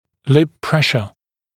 [lɪp ‘preʃə][лип ‘прэшэ]давление губ